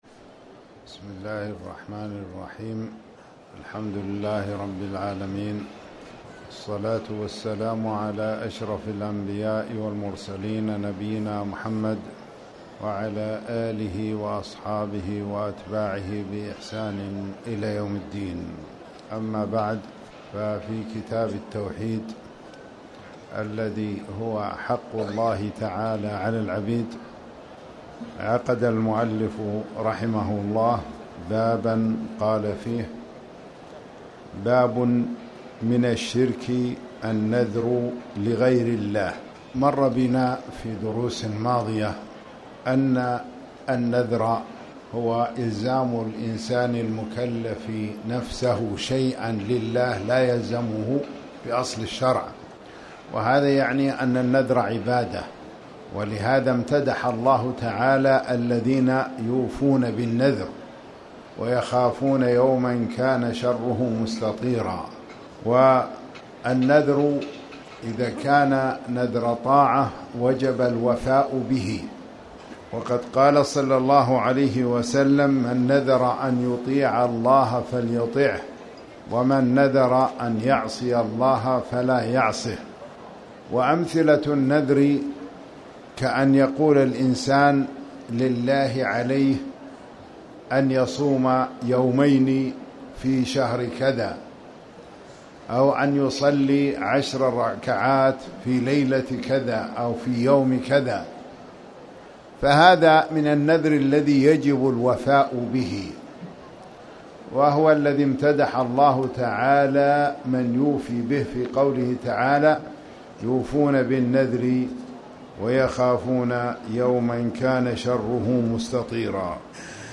تاريخ النشر ١٣ شعبان ١٤٣٩ هـ المكان: المسجد الحرام الشيخ